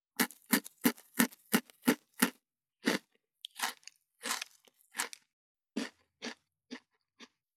17.スナック菓子・咀嚼音【無料効果音】
ASMR/ステレオ環境音各種配布中！！
ASMR